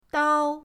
dao1.mp3